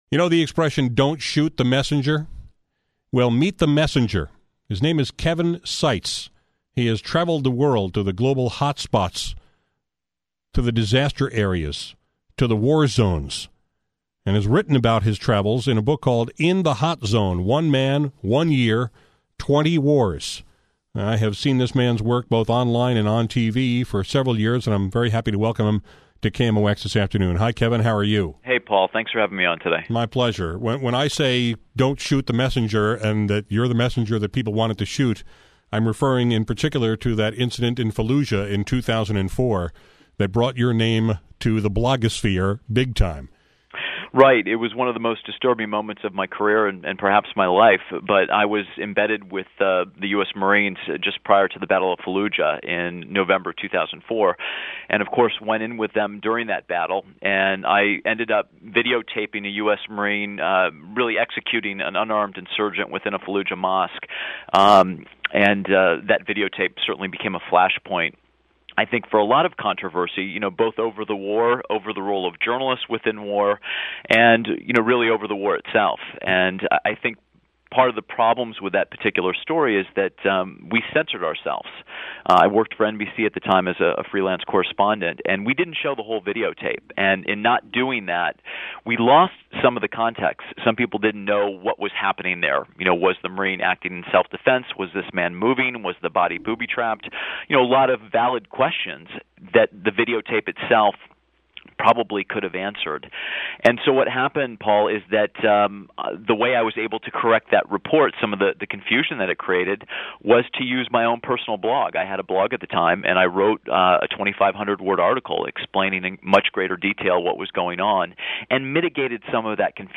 This afternoon on my show, I talked with Sites about some of those adventures, including the infamous incident in Fallujah, where Sites videotaped a US Marine shooting an Iraqi prisoner. We discussed the fallout from that footage, the decision to air it in a censored form, and the international consequences of telling the story.